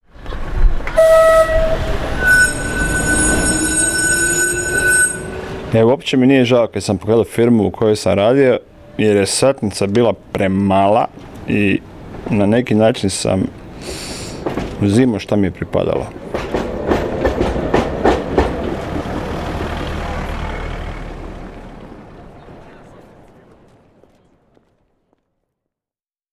radio intervention
STATEMENTS BROADCASTED ON RADIO "SLJEME":